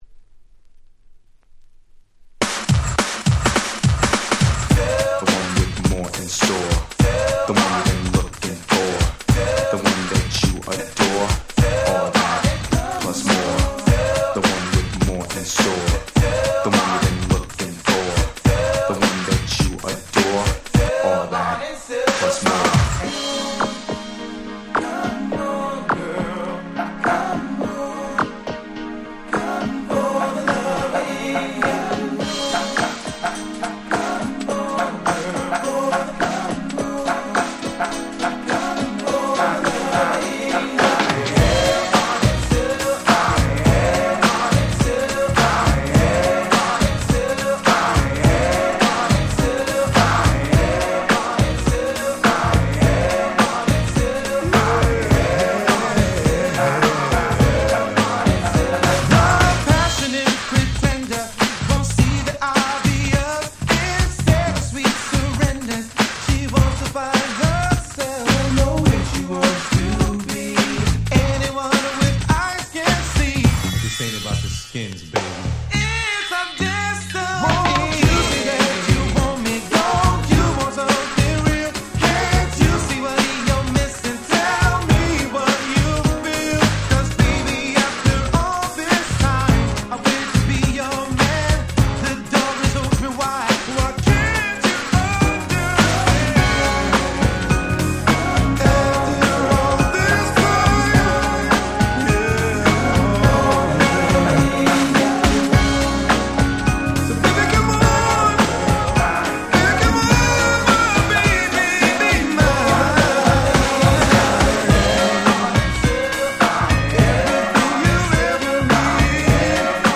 92' Very Nice R&B / New Jack Swing !!
最高のR&B / NJS !!
90's ニュージャックスウィング ハネ系